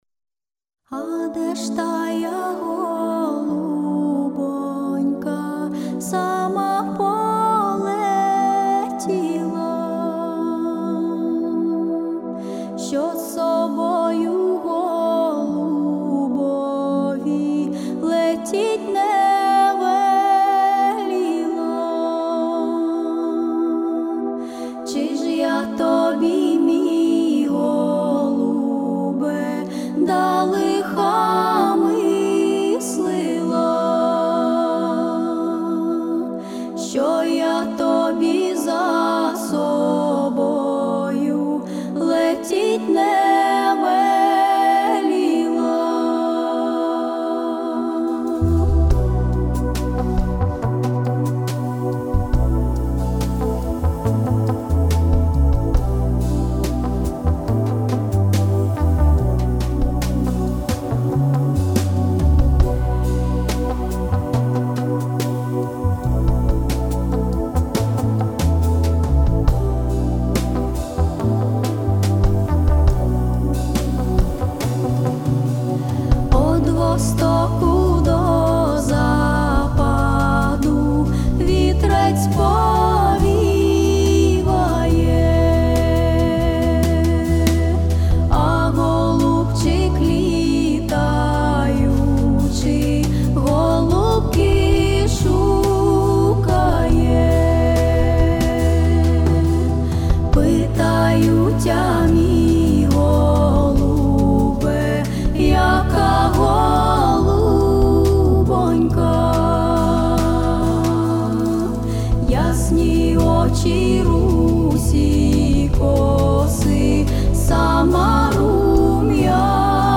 вокал, джаз, Етно
фольк